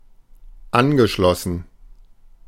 Ääntäminen
US : IPA : [kəˈnɛktɪd]